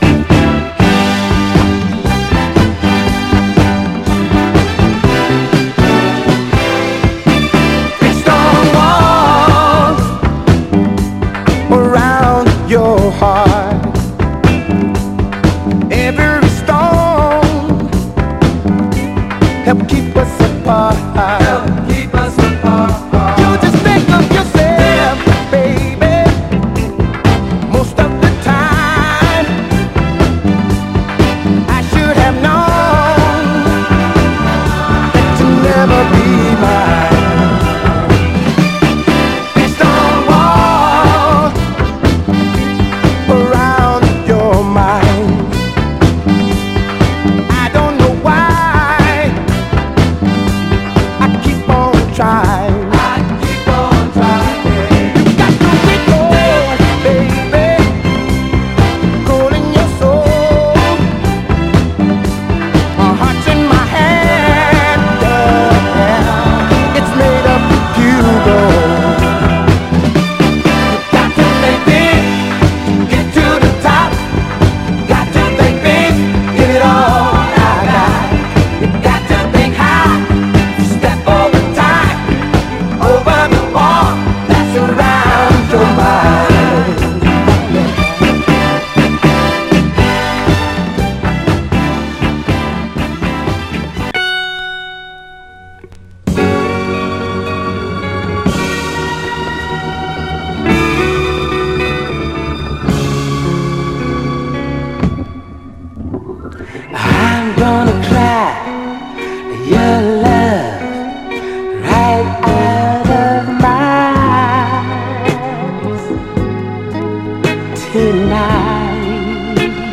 静かなB面頭でごく僅かに音に影響しますが、それ以外はプレイ良好です。
※試聴音源は実際にお送りする商品から録音したものです※